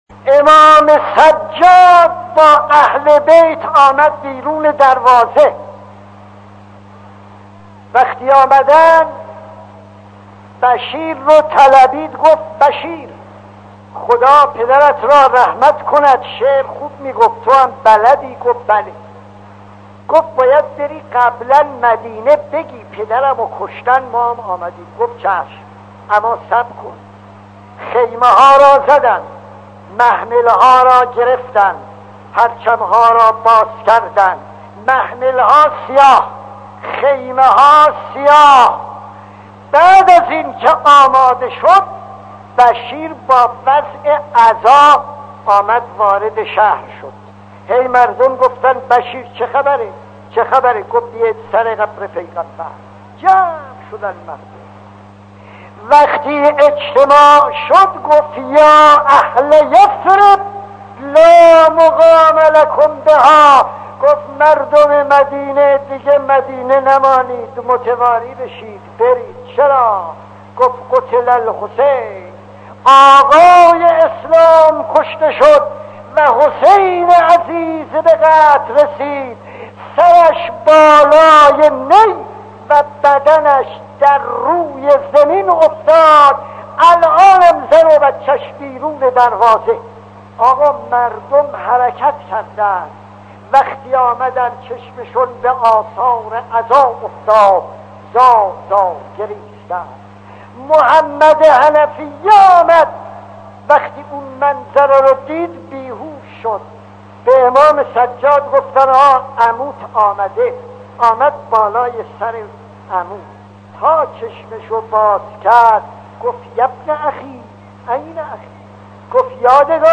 داستان 17 خطیب: استاد فلسفی مدت زمان: 00:02:19